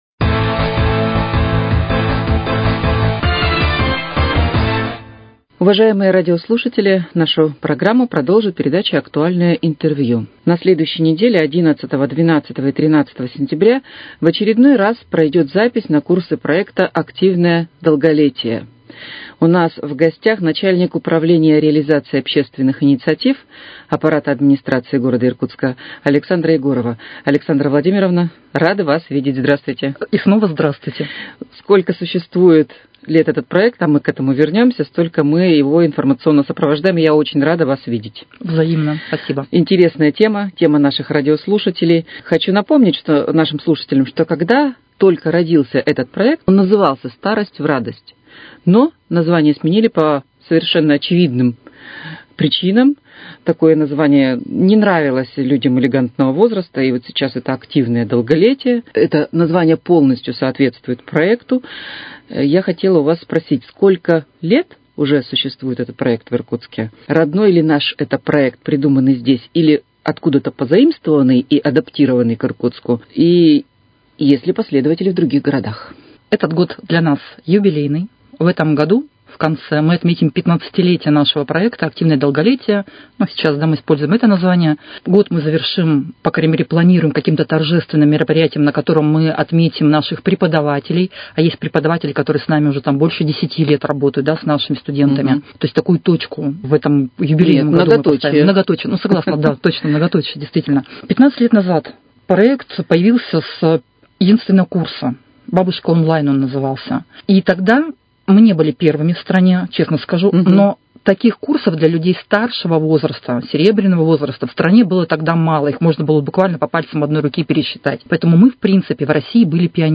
Актуальное интервью: О старте проекта «Активное долголетие»